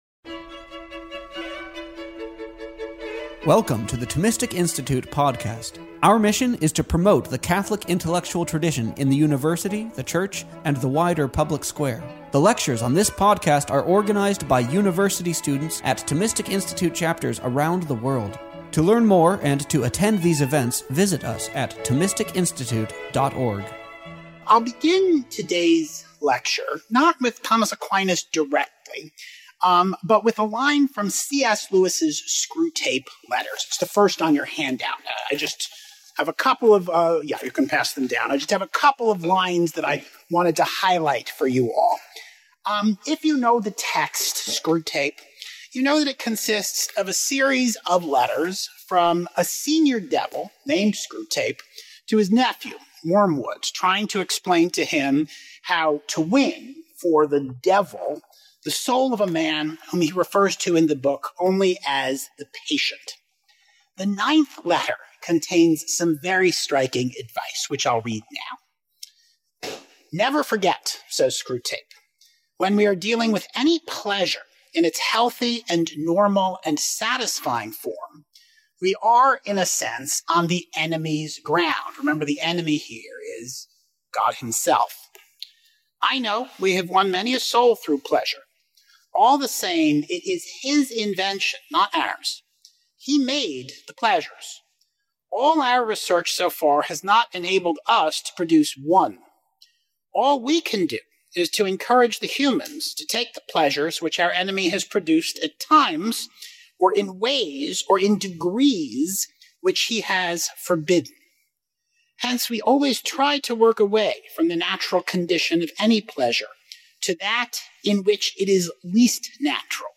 This lecture was given on December 4th, 2025, at Southern Methodist University.